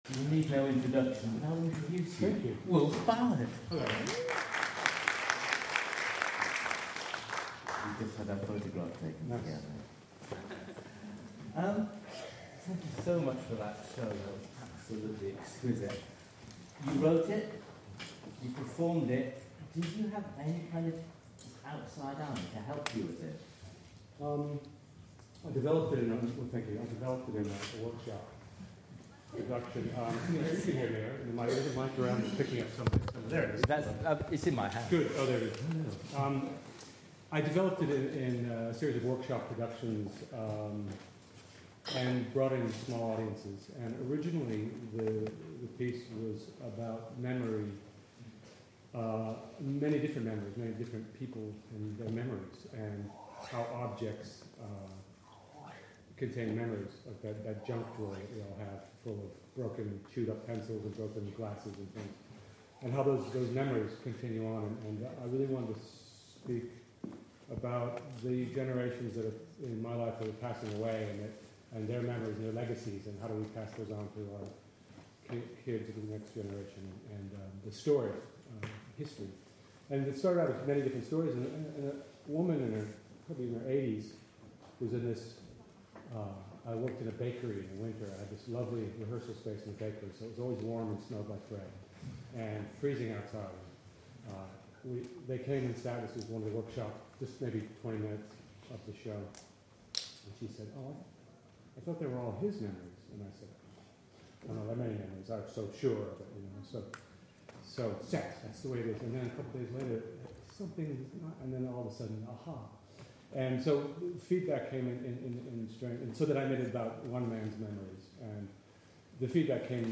Following on from my post about mime a few days ago, I have found audio interviews with the some of the performers I mentioned, courtesy of Exeunt Magazine.